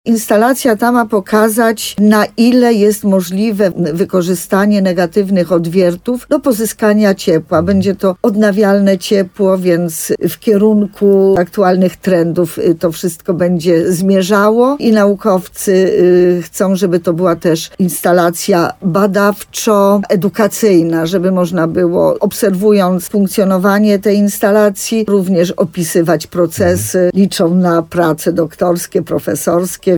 – To będzie pierwsza w Polsce tak zwana sucha geotermia – mówi wójt gminy Sękowa Małgorzata Małuch. Baseny w Sękowej powinny być gotowe w 2027 roku. Termy będą pilotażowym projektem Ministerstwa Klimatu i Środowiska.
To będzie inwestycja samowystarczalna energetycznie – mówi wójt gminy Sękowa, Małgorzata Małuch.